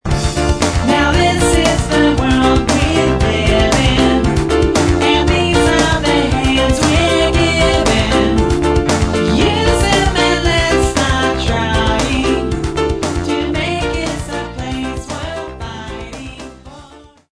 Samples Of Cover Tunes With Vocals